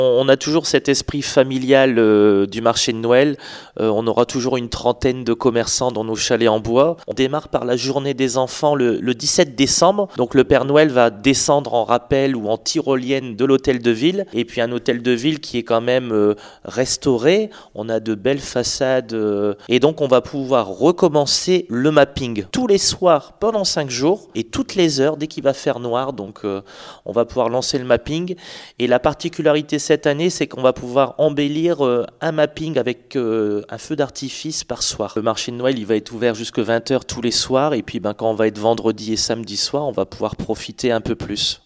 Une trentaine de chalets , la visite du Père Noël , des animations et le retour du mapping chaque jour ..LOGO HAUT PARLEURArques veut garder l’esprit familial ….Ecoutez Benoit ROUSSEL Maire de la ville